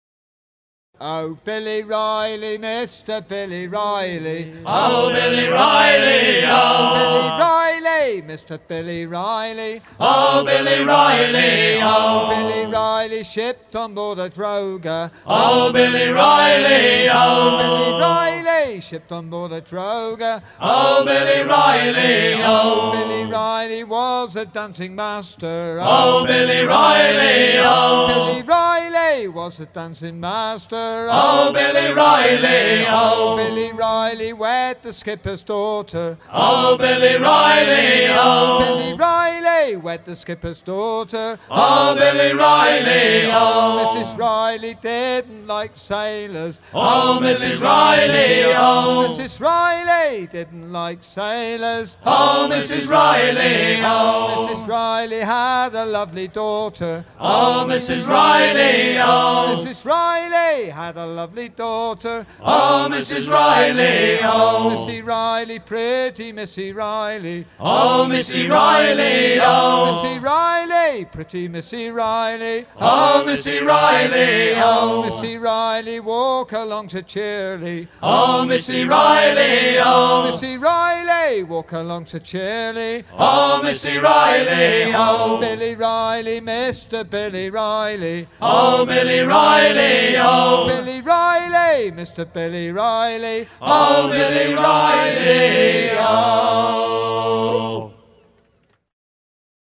Chantey en usage dès les années 1850
circonstance : maritimes